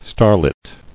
[star·lit]